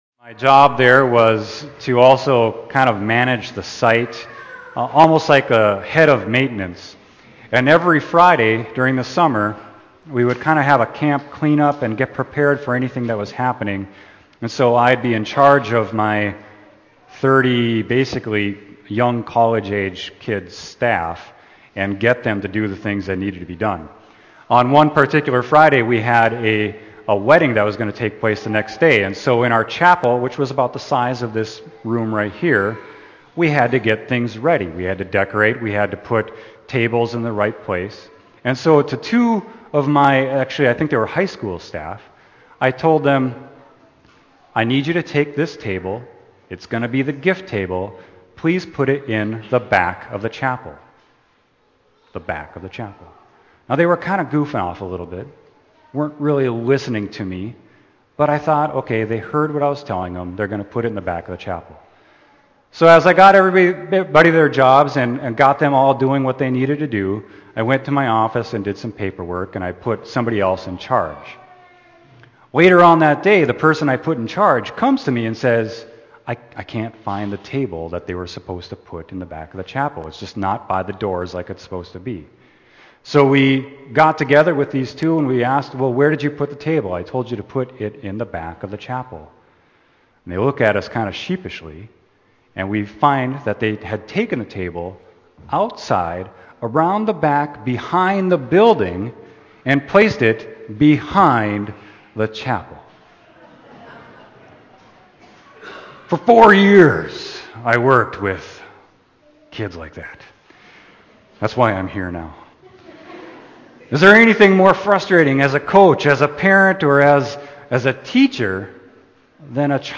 Scriptures: Isaiah 50:4-9a; Psalm 116:1-9; James 3:1-12; Mark 8:27-38 Sermon: Isaiah 50.4-9a